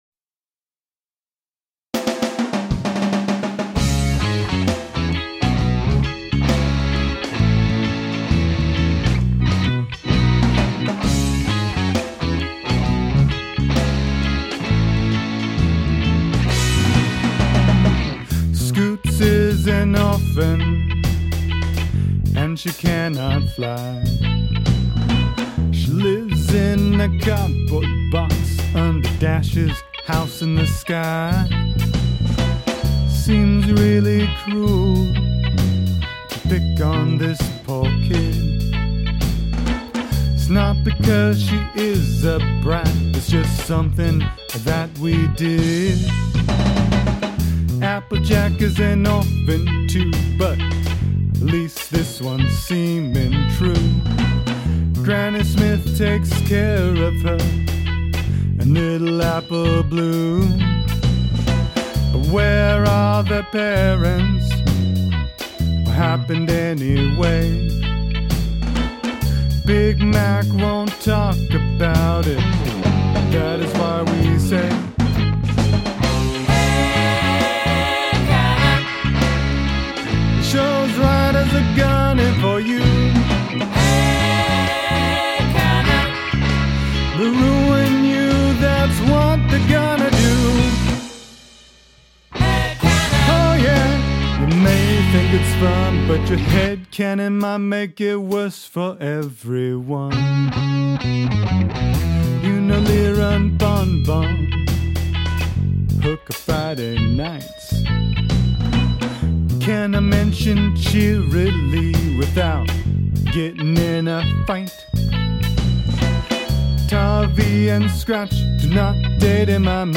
You'll have to deal with my lullaby voice.